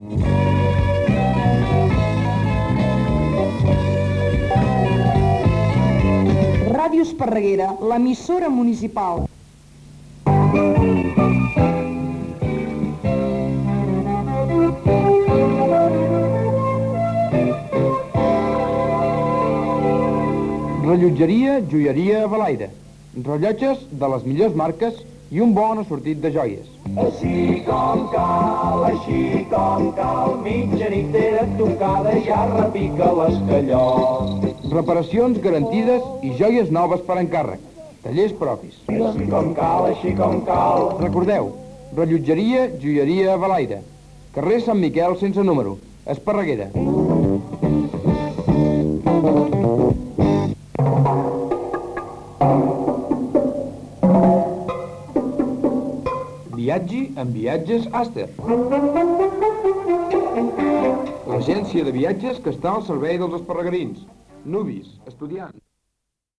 Indicatiu i publicitat
FM